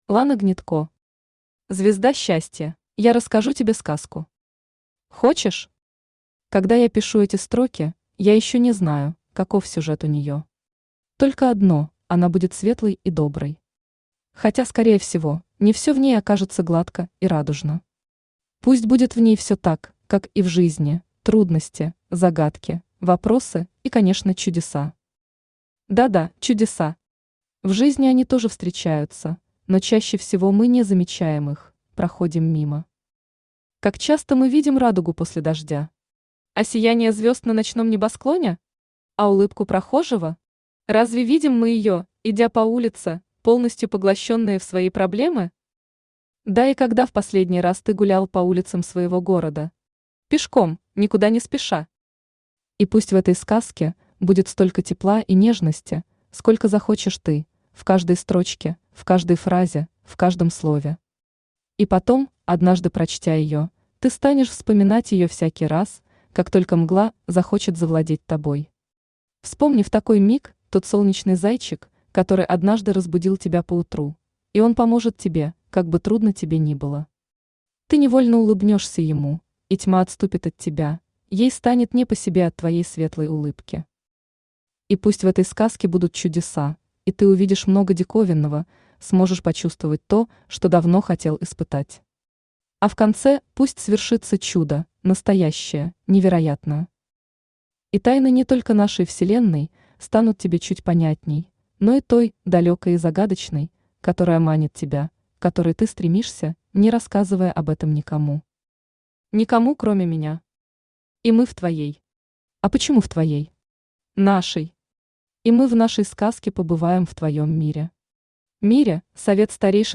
Аудиокнига Звезда Счастья | Библиотека аудиокниг
Aудиокнига Звезда Счастья Автор Лана Гнедко Читает аудиокнигу Авточтец ЛитРес.